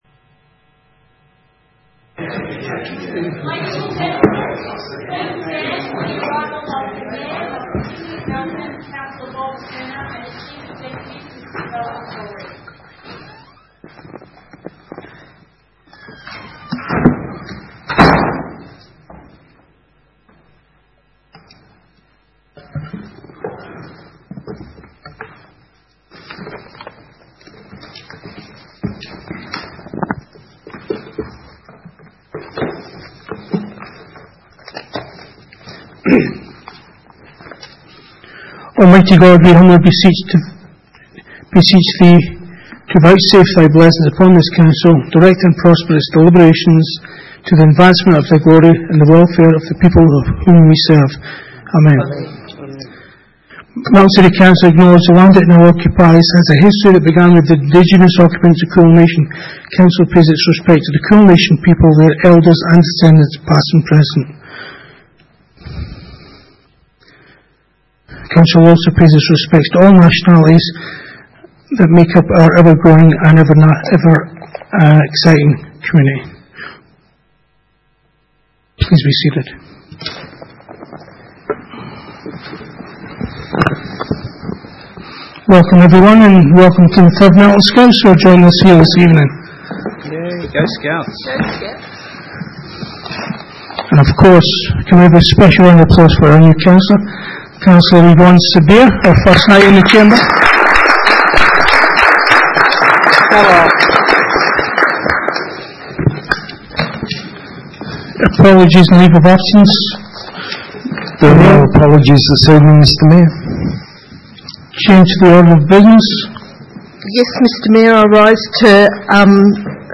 Ordinary Meeting 24 June 2019
Melton Civic Centre, 232 High Street, Melton, 3337 View Map